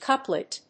/kˈʌplət(米国英語)/